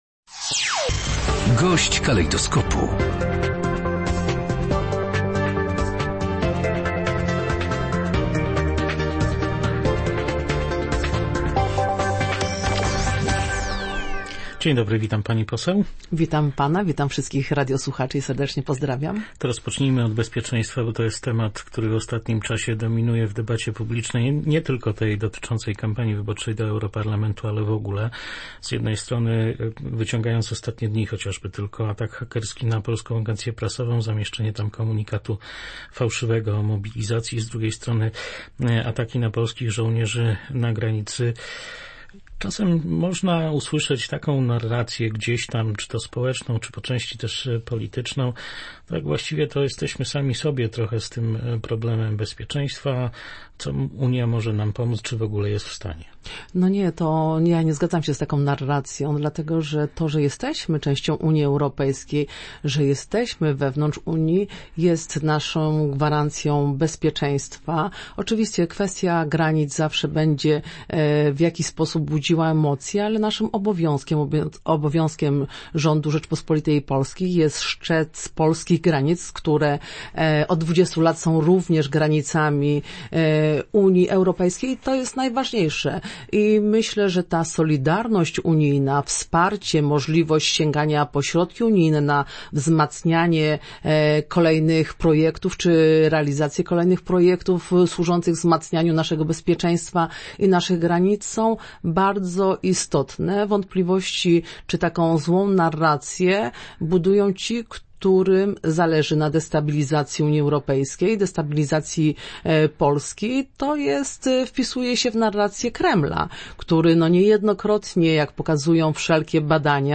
Jesteśmy w Unii Europejskiej od ponad 20 lat i to jest naszą gwarancją bezpieczeństwa. Mówienie, że musimy sobie radzić sami to narracja, którą kreuje Rosja – powiedziała w Polskim Radiu Rzeszów europosłanka KO Elżbieta Łukacijewska, która dodała także, że cała Unia po wybuchu wojny na Ukrainie zdała sobie sprawę, jak ważna dla wszystkich jest wspólne stanowisko w kwestii bezpieczeństwa.